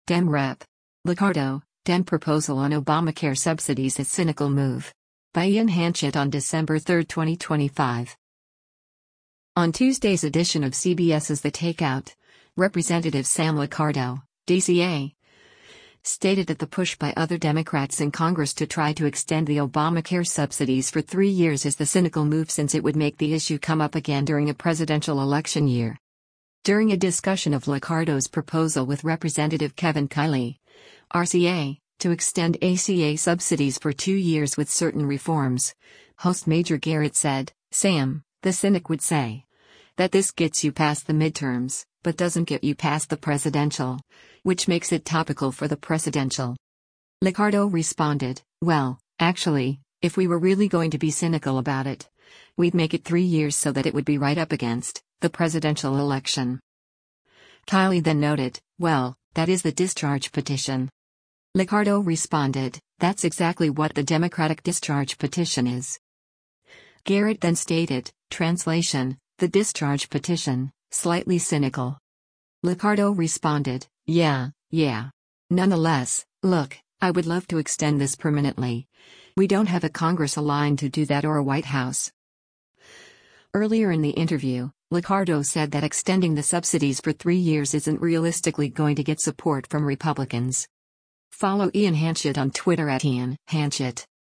On Tuesday’s edition of CBS’s “The Takeout,” Rep. Sam Liccardo (D-CA) stated that the push by other Democrats in Congress to try to extend the Obamacare subsidies for three years is the cynical move since it would make the issue come up again during a presidential election year.